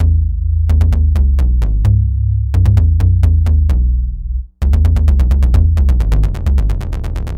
带低音的低音
描述：主低音
Tag: 130 bpm Electro Loops Bass Wobble Loops 1.24 MB wav Key : A